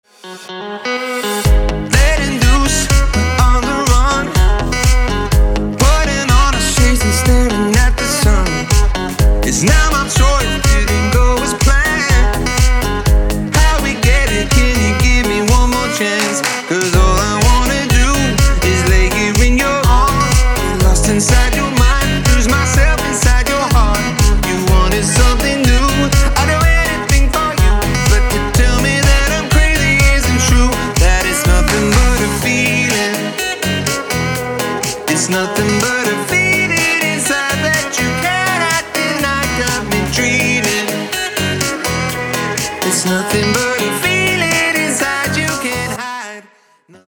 • Качество: 320, Stereo
мужской вокал
заводные
электронная музыка
электрогитара
house